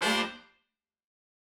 GS_HornStab-Gmin+9sus4.wav